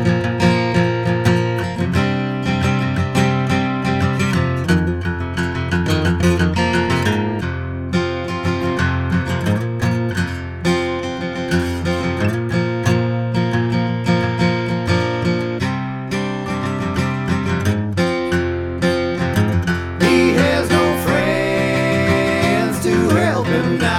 no Backing Vocals Soundtracks 3:02 Buy £1.50